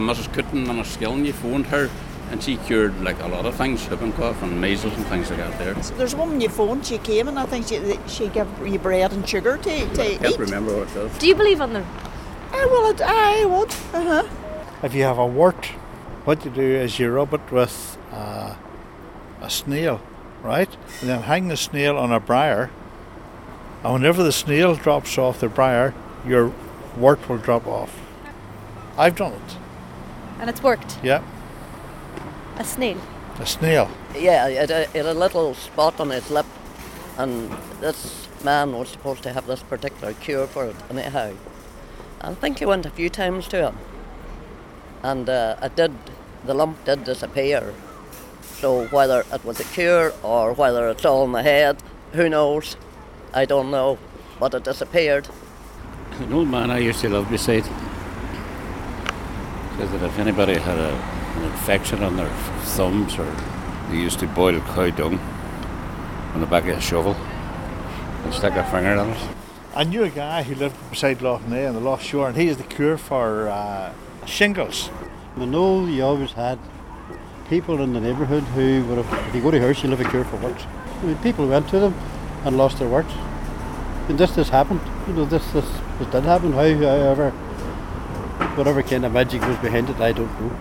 has been asking the good people of Derry for their own old fashioned 'cures'....